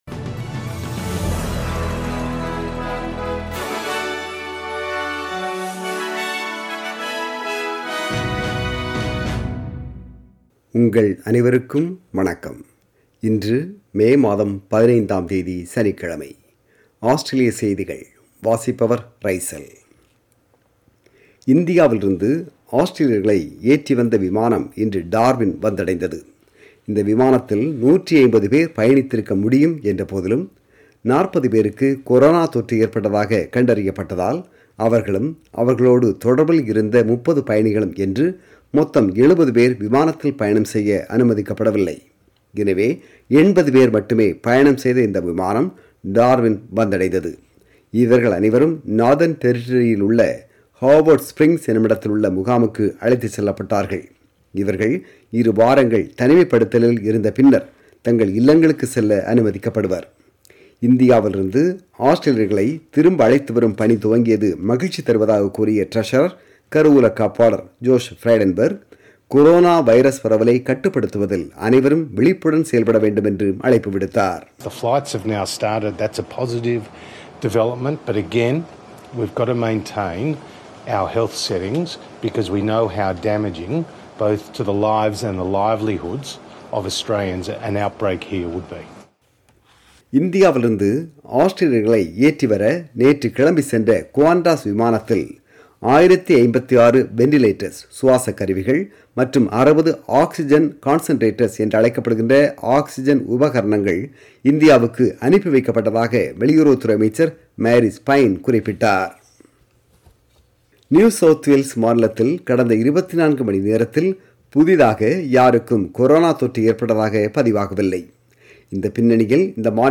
ஆஸ்திரேலிய செய்திகள்